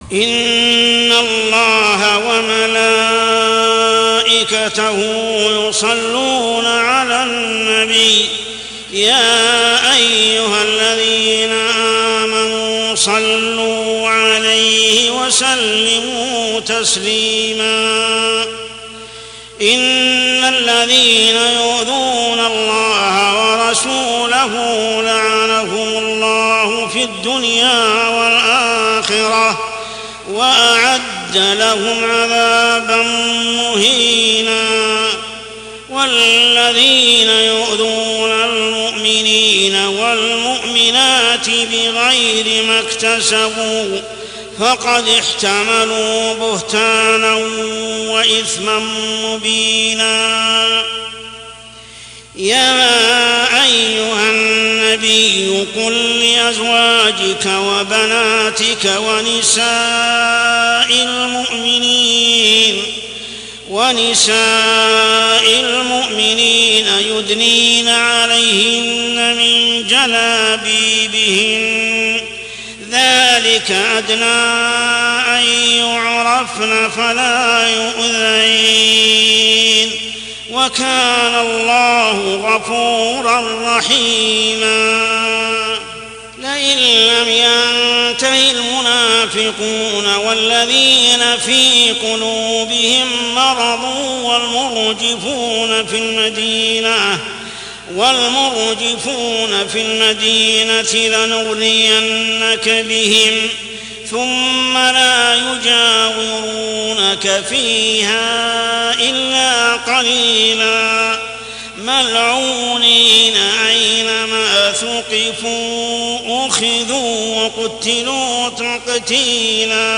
عشائيات شهر رمضان 1424هـ سورة الأحزاب 56-62 | Isha prayer Surah Al-Ahzab > 1424 🕋 > الفروض - تلاوات الحرمين